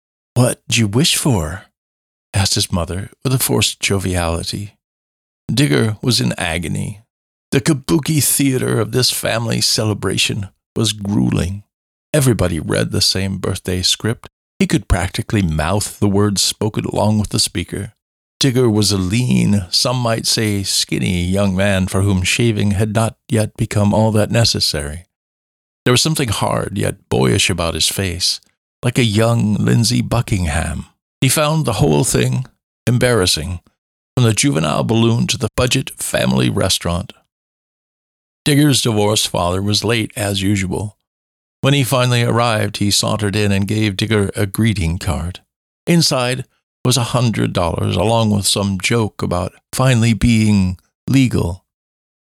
Audiobook Narration
3rd Person w/Characters